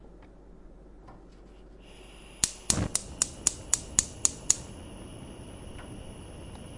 斯密特厨房 " 飞行员和火焰01
描述：在Zoom H4n上录制96 KHz 32位立体声
Tag: 厨房 国内的声音 现场记录 器具 烹饪